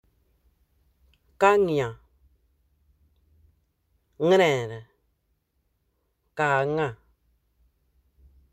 Accueil > Prononciation > ng > ng